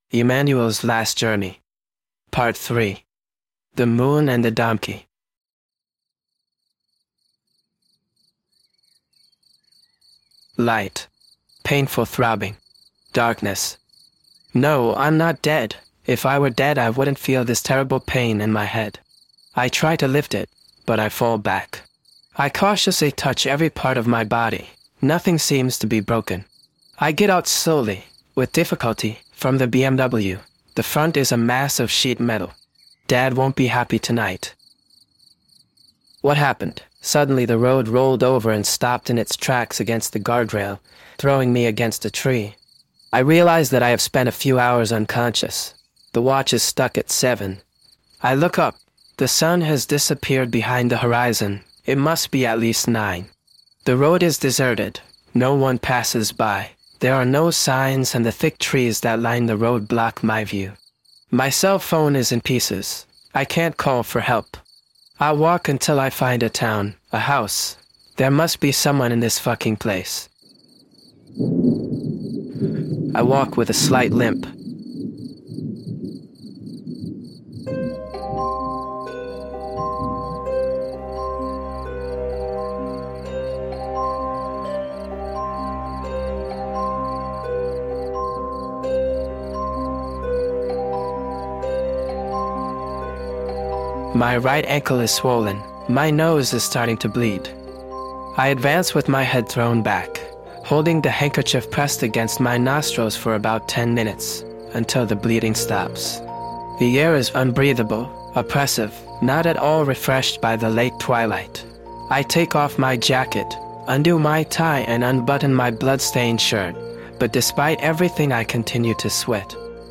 The interpreter is Paul Emmanuel (AI).
The final music is a cover of "Another Day" by Roy Harper in the version by This Mortal Coil.